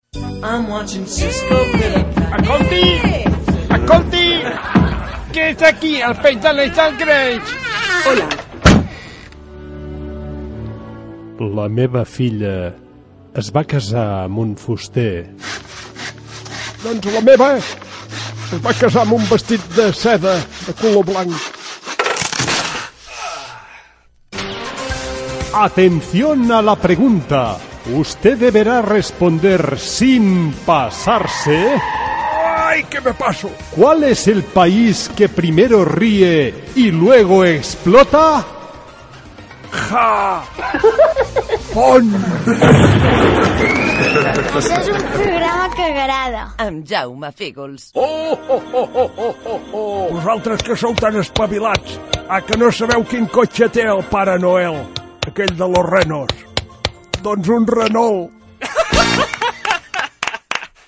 Miniespai humorístic: el Japó i el cotxe del Pare Noel amb identificació del programa
Tot ben guarnit amb molts efectes de so, ben picat i amb moltes veus diferents.